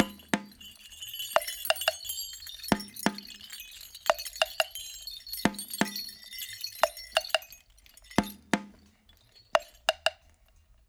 88-PERC3.wav